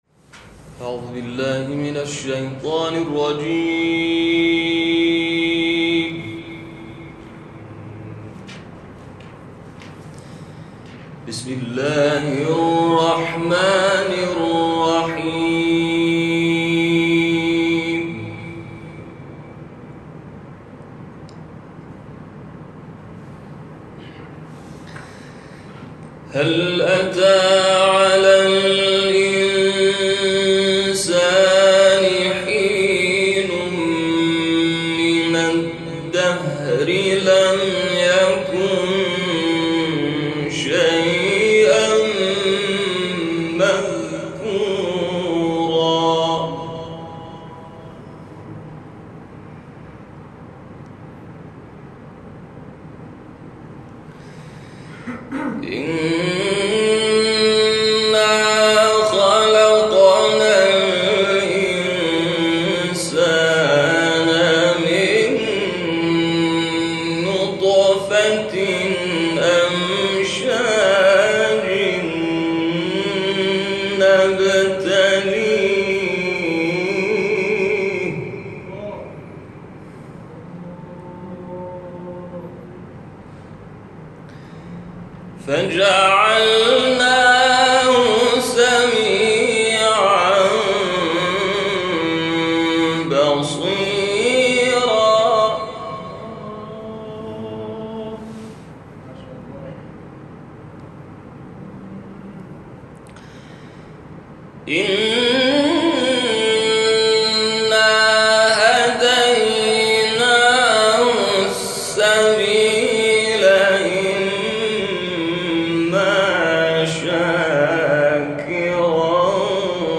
تلاوت سوره انسان
این تلاوت شب گذشته اجرا شده است و مدت زمان آن 24 دقیقه است.